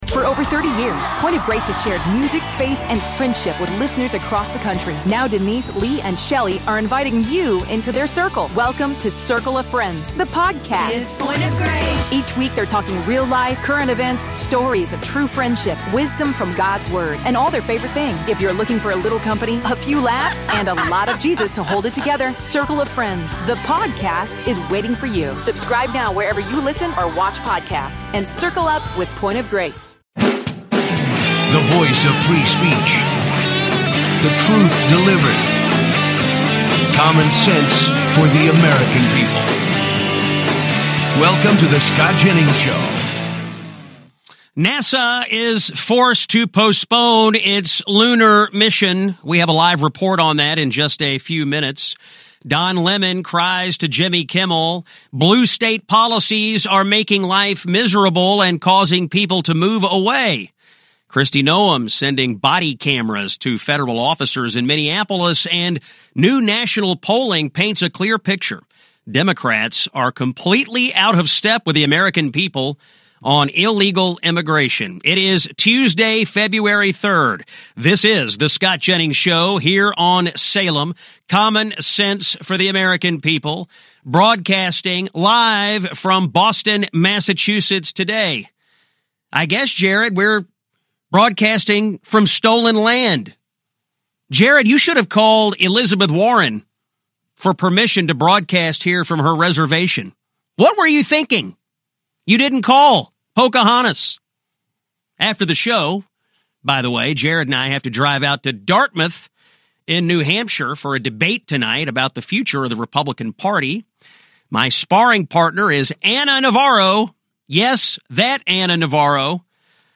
LIVE FROM BOSTON TO DISCUSS ICE WATCH BLOCKADES, IRANIAN DRONES, & NASA LAUNCH